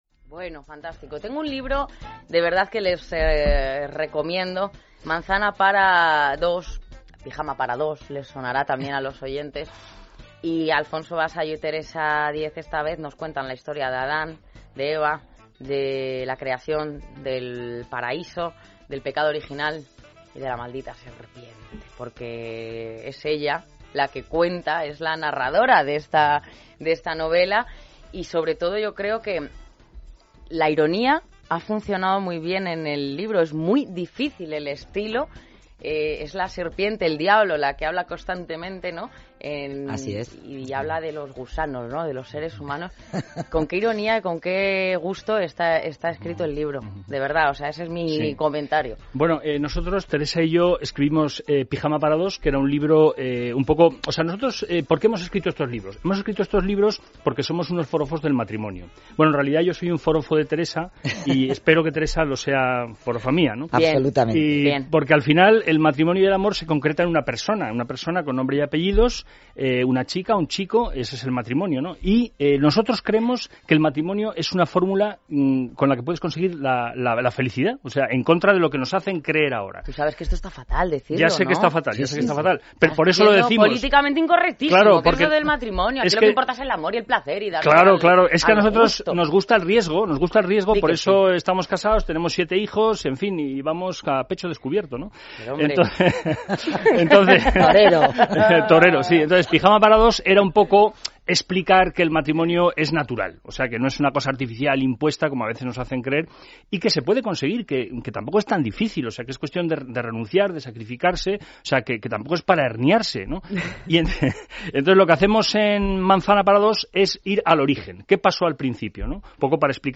Nos entrevistan en esRadio sobre 'Manzana' - Pijama para dos